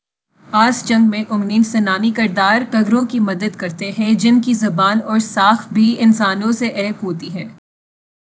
deepfake_detection_dataset_urdu / Spoofed_TTS /Speaker_04 /104.wav